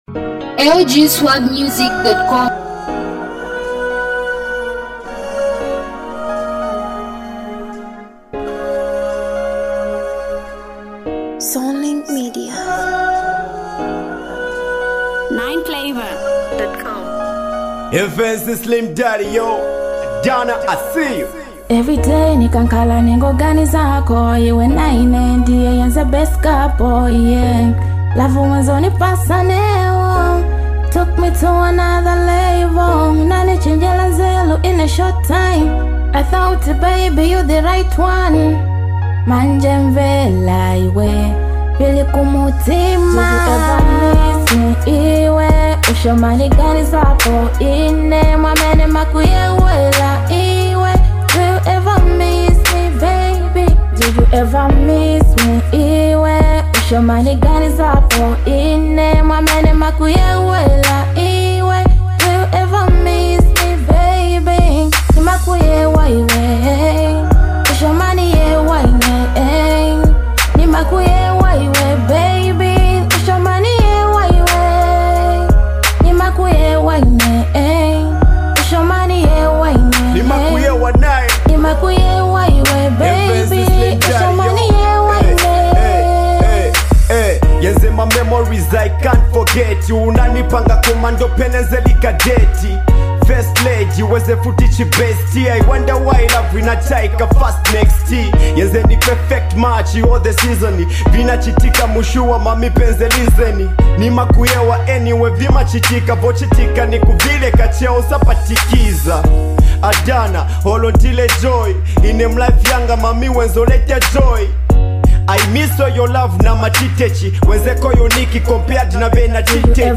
love song